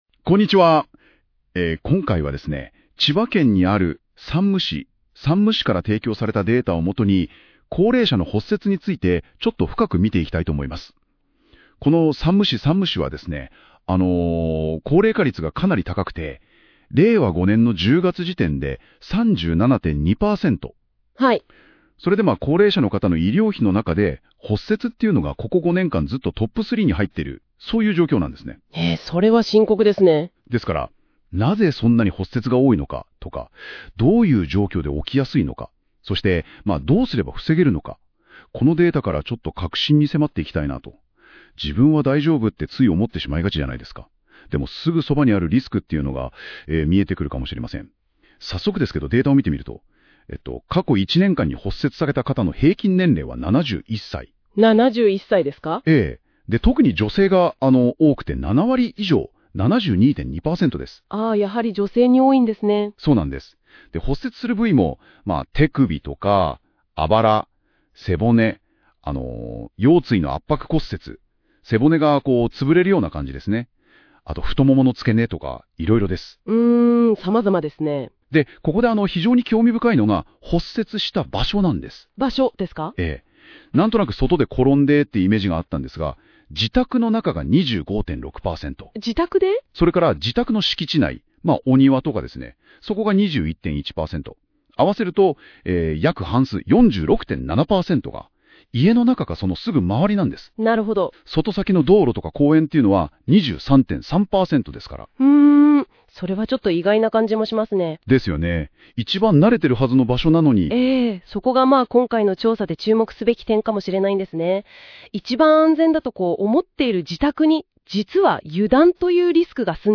AIボイスで転倒骨折の実態をご紹介
バナー（AIボイス）